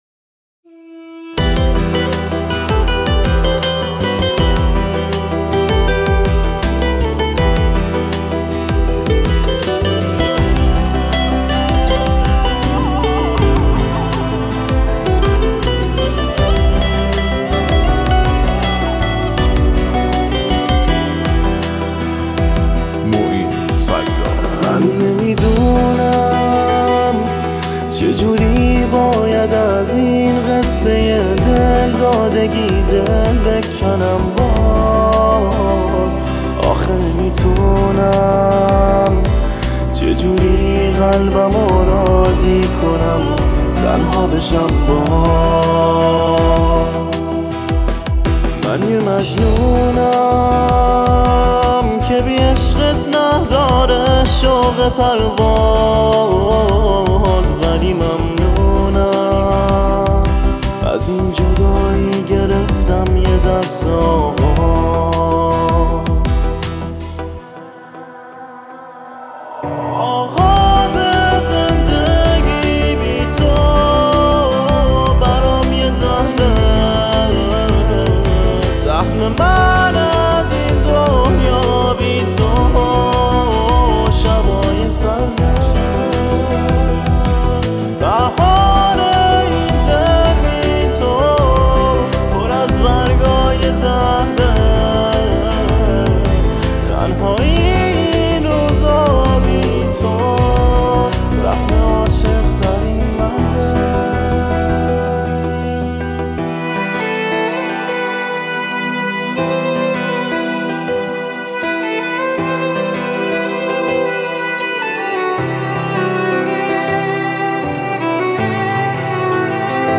گیتار
ویولون
آهنگ ایرانی